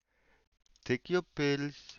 take-pills.wav